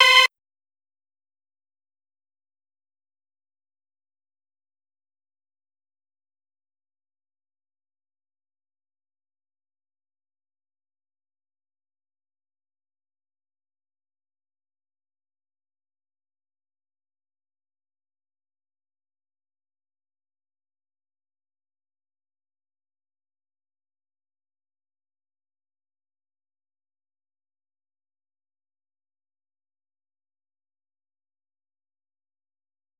Synths